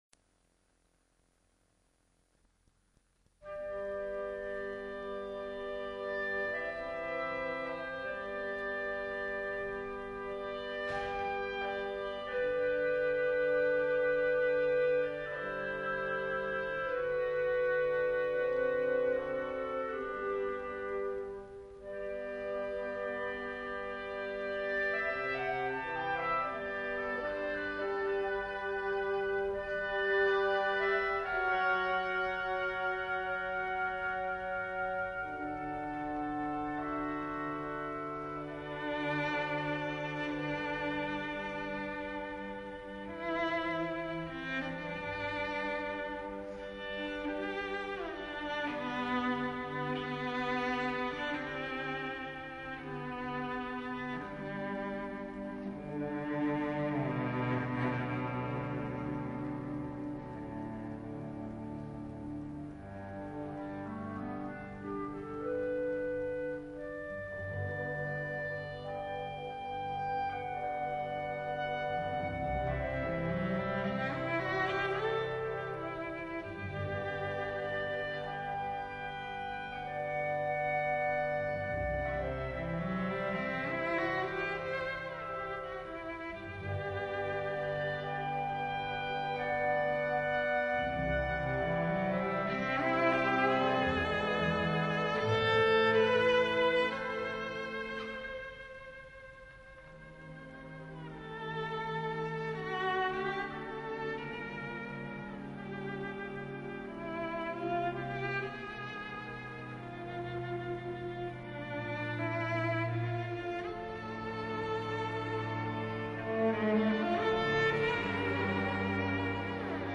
[2004-12-26]德沃夏克 b小调大提琴协奏曲